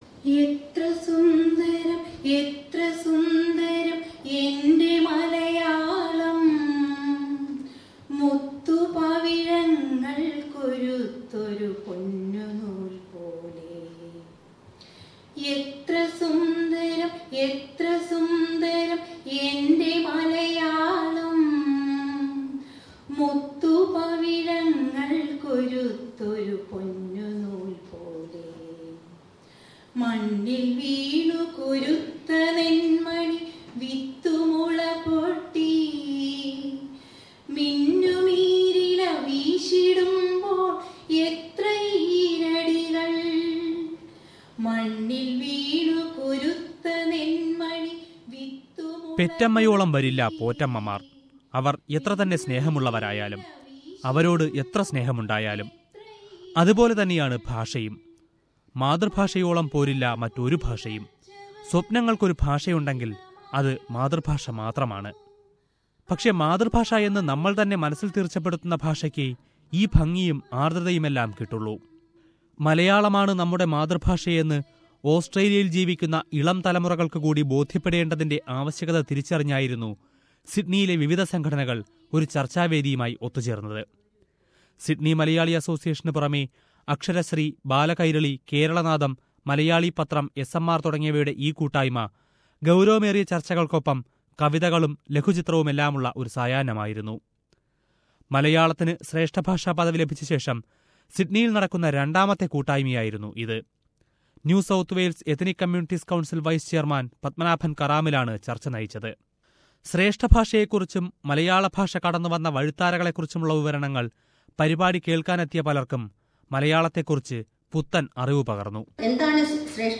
Australian Malayalees are coming together again to celebrate the new stature of their mother tongue. Various Malayalee organisations in Sydney came together to discuss and celebrate. It was an evening filled with insights, poems and music...